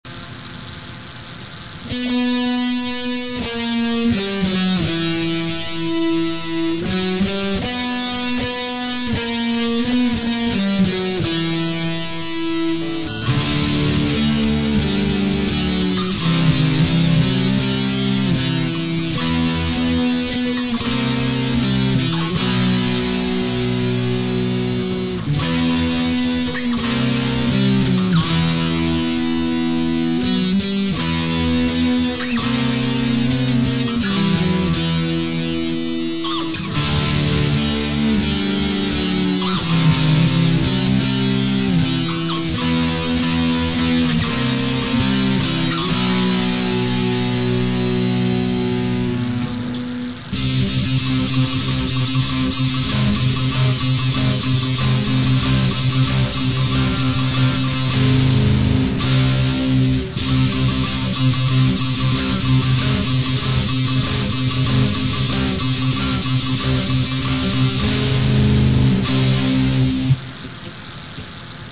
Музыка: с помощью моей палко